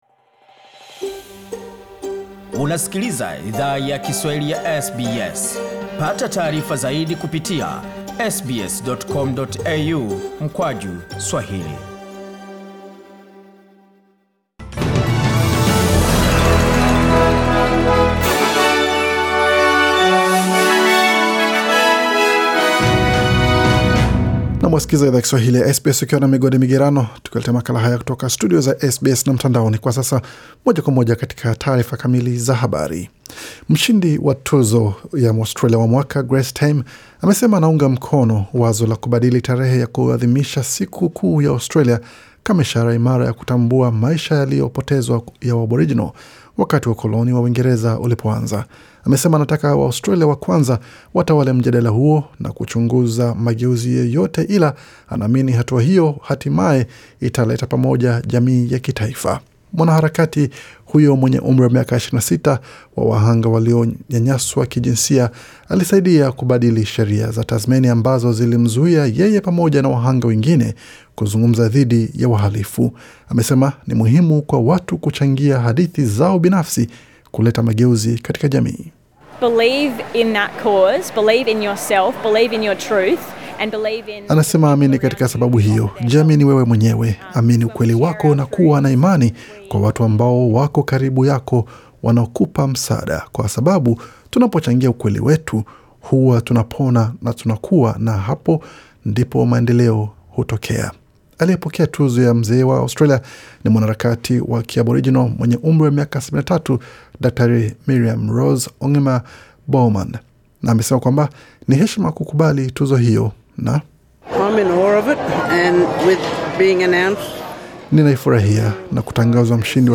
Taarifa ya habari 26 Januari 2021